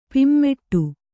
♪ pimmeṭṭu